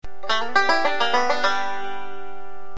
For instance listen to the sound bite for the difference in this classic lick in Scruggs style and Reno style.
This particular lick is the result of  Don not using the “forward backward roll” that is a fixture in Scruggs style.  It is a subtle difference the way Don does it but it is a crucial part of Reno’s sound.